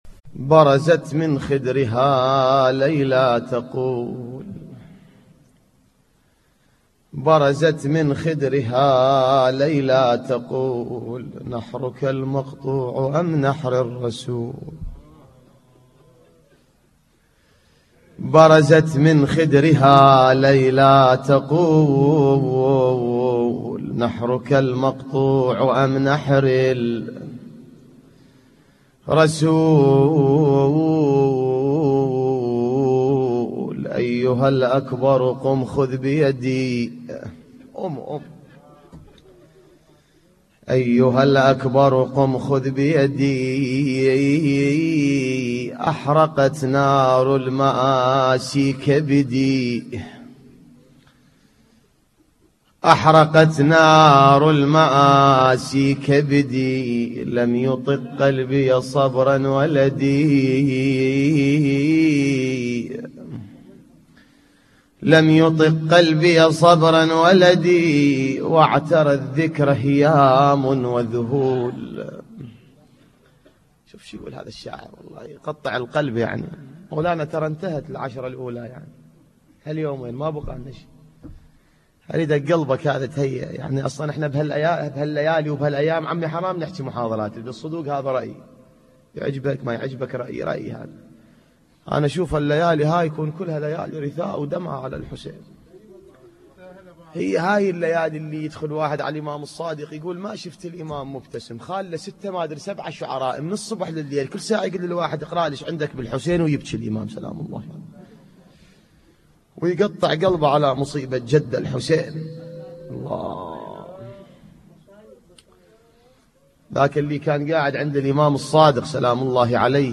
نواعي حسينية